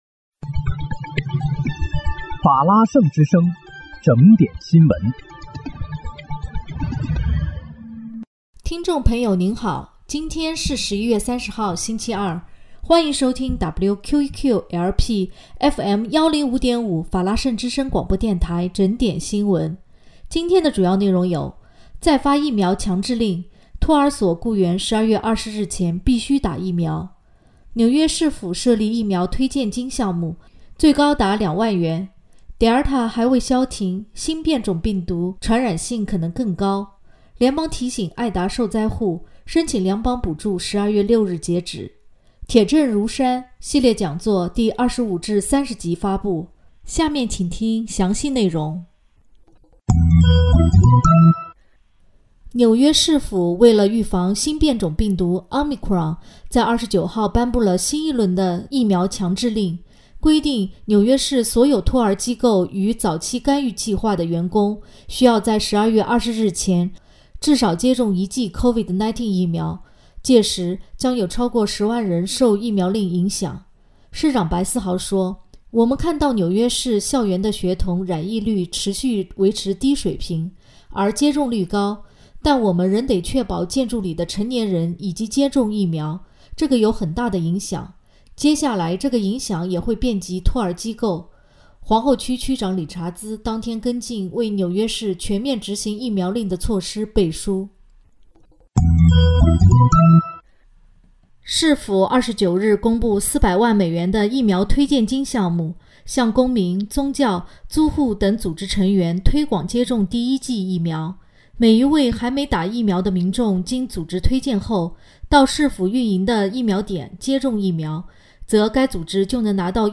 11月30日（星期二）纽约整点新闻
听众朋友您好！今天是11月30号，星期二，欢迎收听WQEQ-LP FM105.5法拉盛之声广播电台整点新闻。